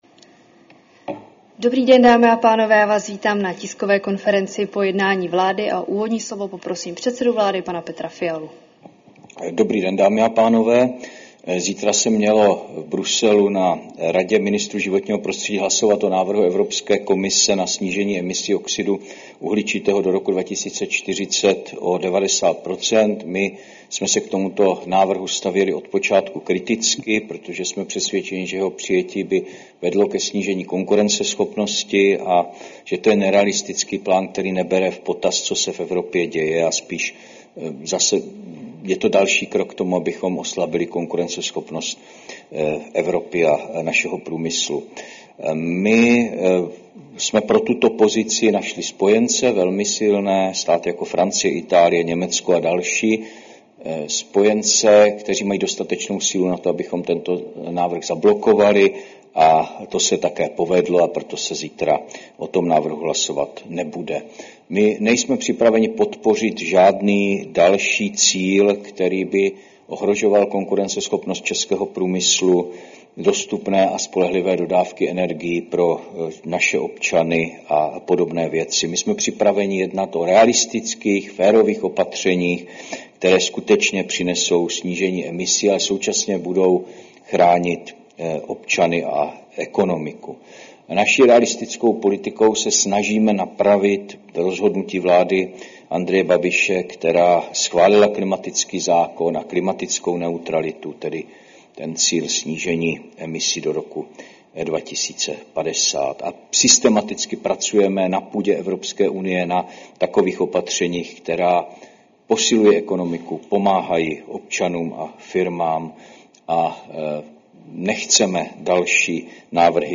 Tisková konference po jednání vlády, 17. září 2025